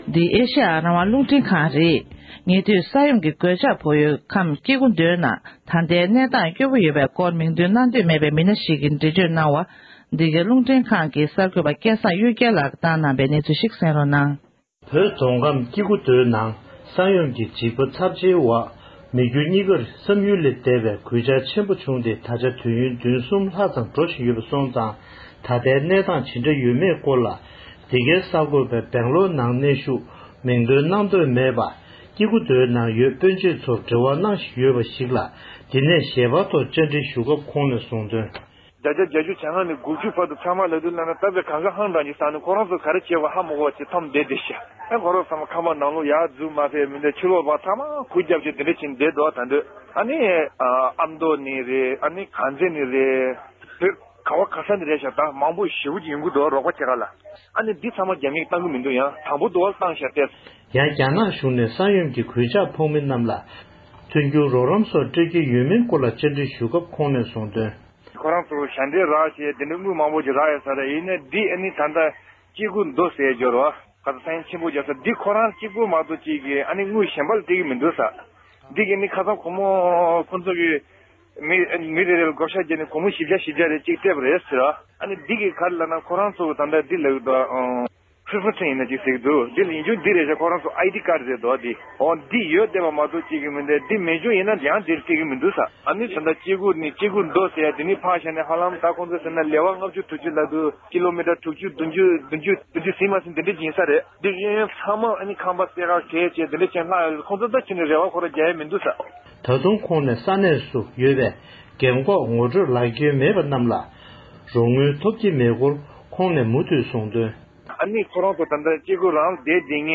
སྒྲ་ལྡན་གསར་འགྱུར། སྒྲ་ཕབ་ལེན།
མིང་འདོན་གནང་འདོད་མེད་པའི་བོད་མི་ཞིག་ལ།